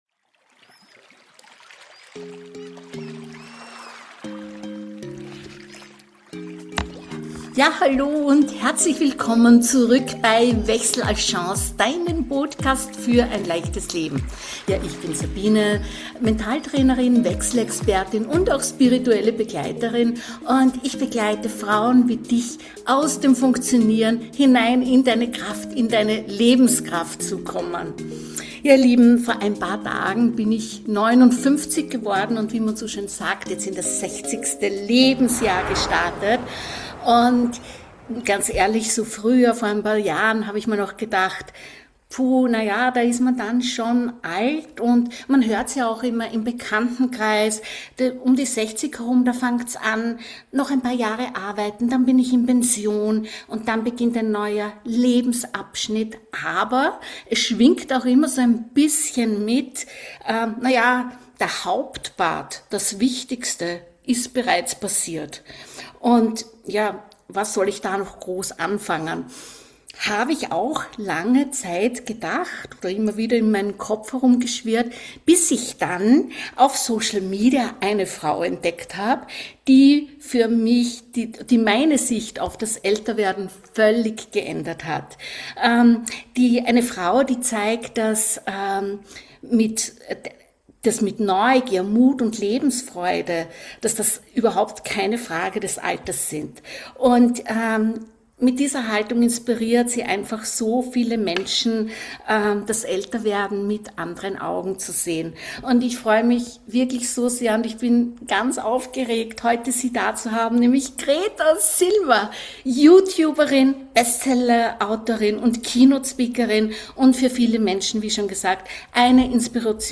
Ein Gespräch, das zeigt, dass Älterwerden nicht bedeutet, weniger zu leben – sondern vielleicht bewusster, freier und neugieriger.